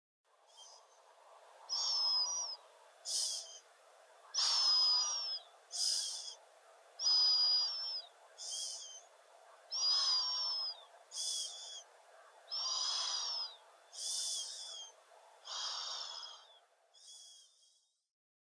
Звуки сопения
Шумное сопение человека во сне